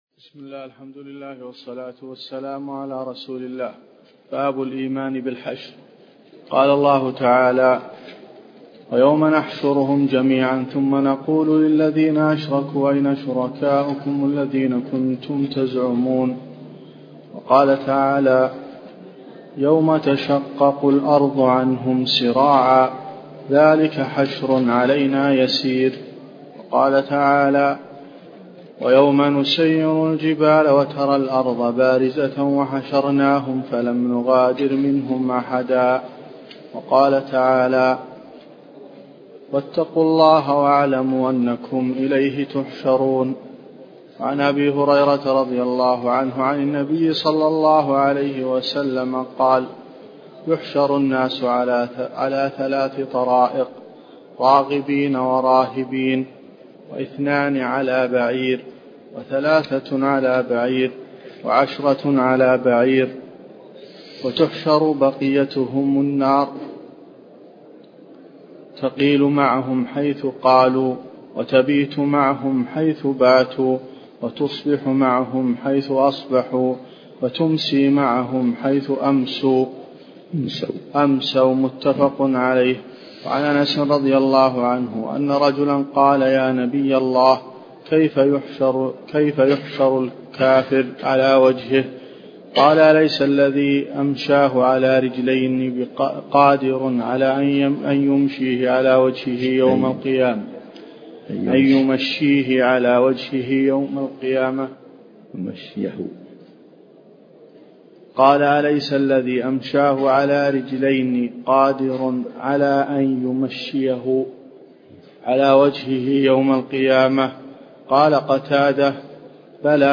تفاصيل المادة عنوان المادة الدرس (15) شرح المنهج الصحيح تاريخ التحميل الأحد 15 يناير 2023 مـ حجم المادة 25.51 ميجا بايت عدد الزيارات 246 زيارة عدد مرات الحفظ 95 مرة إستماع المادة حفظ المادة اضف تعليقك أرسل لصديق